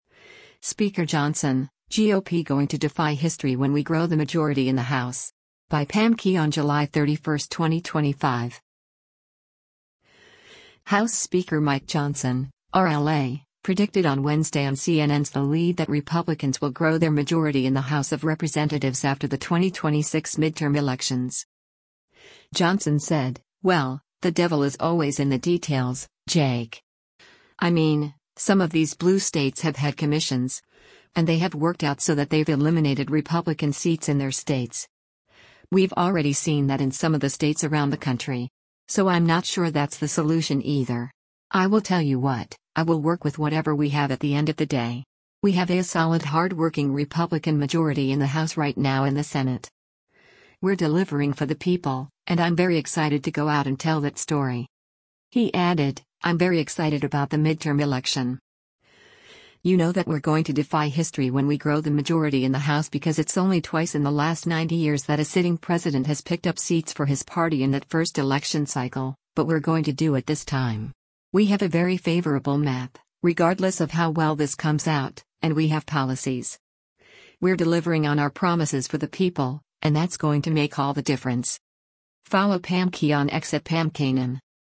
House Speaker Mike Johnson (R-LA) predicted on Wednesday on CNN’s “The Lead” that Republicans will grow their majority in the House of Representatives after the 2026 midterm elections.